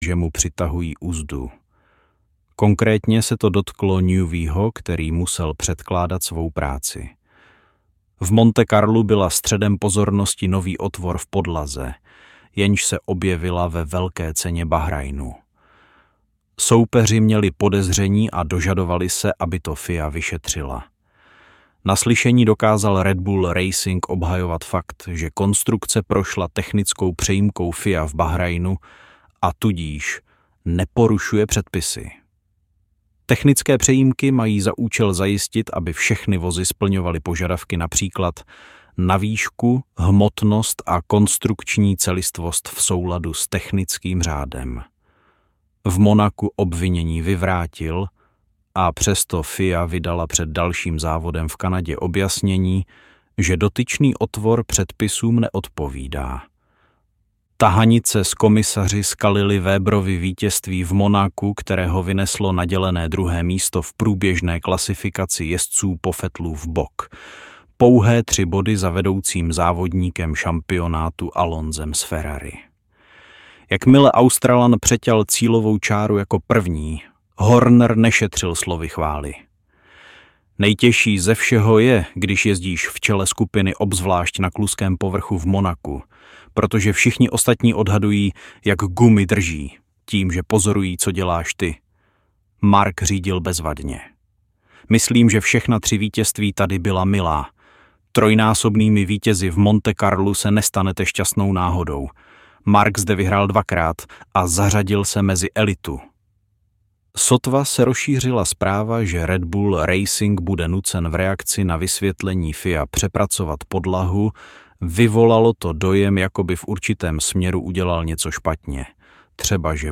Rychlá křídla audiokniha
Ukázka z knihy